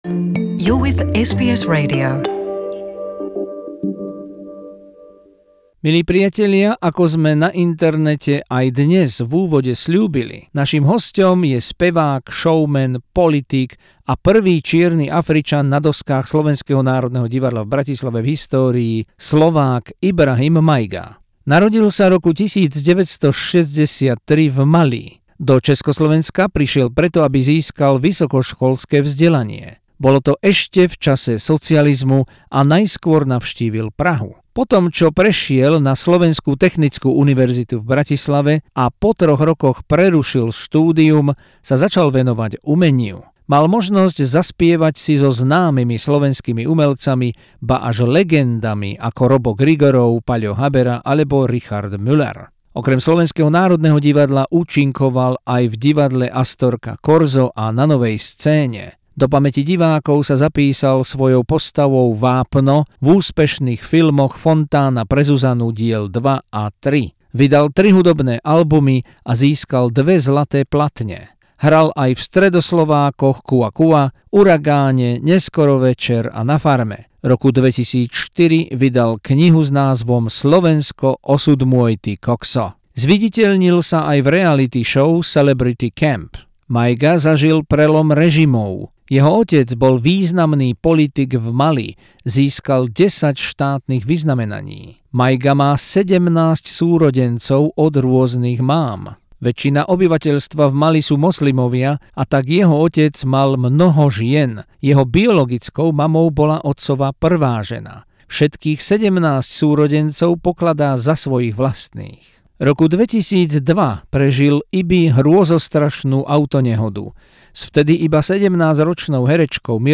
Exkluzívny rozhovor